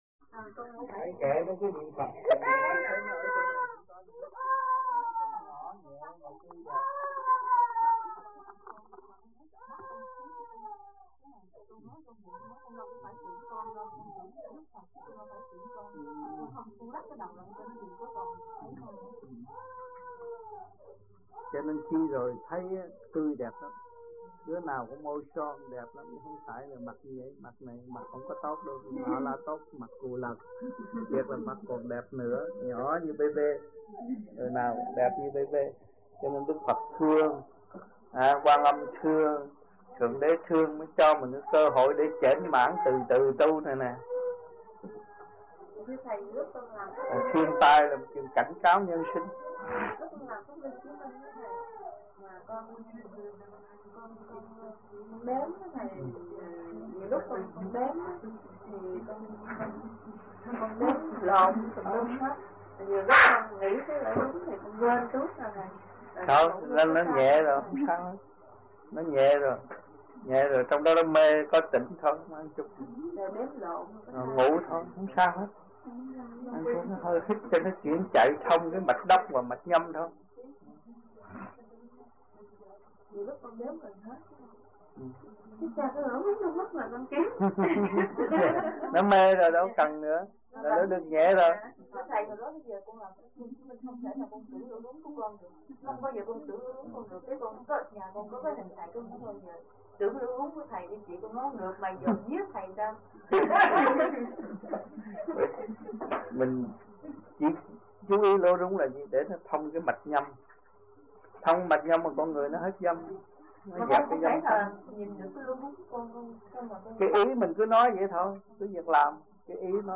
1980-11-20 - AMPHION - THUYẾT PHÁP 09